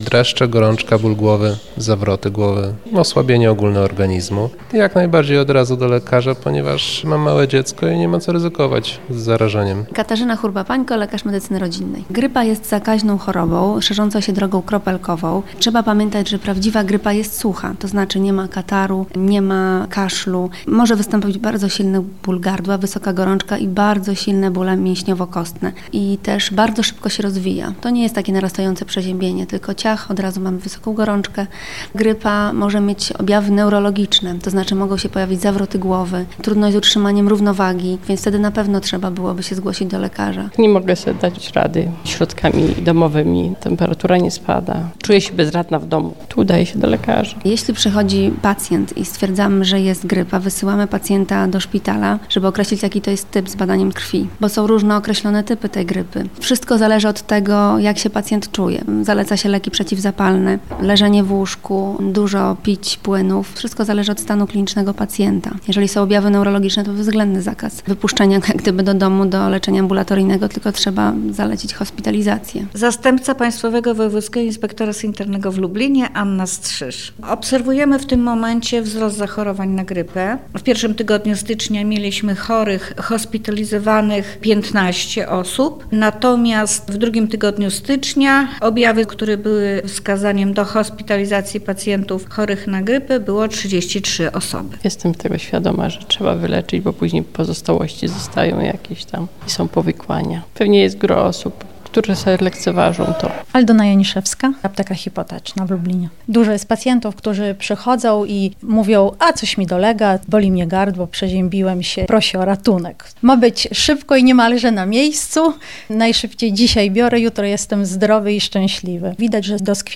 – Dreszcze, gorączka, ból i zawroty głowy i osłabienia organizmu – mówi jeden z mieszkańców Lublina.